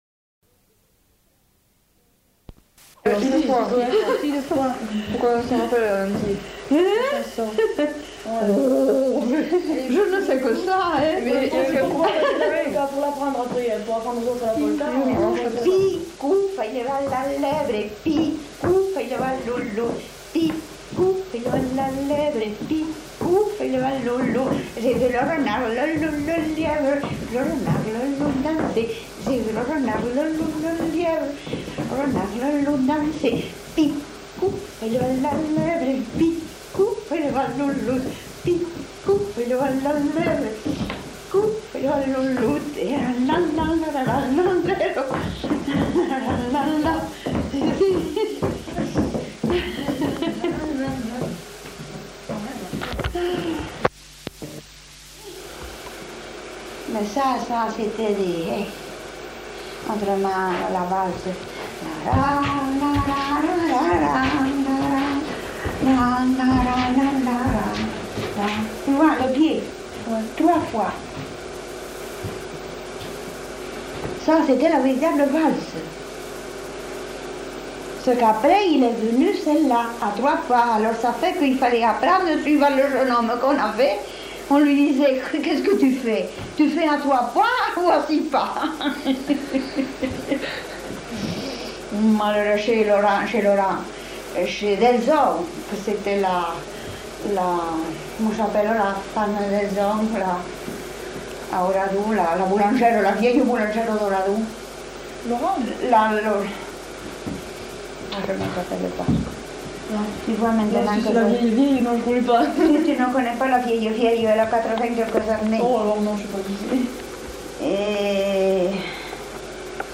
Aire culturelle : Haut-Agenais
Genre : chant
Effectif : 1
Type de voix : voix de femme
Production du son : chanté
Danse : polka piquée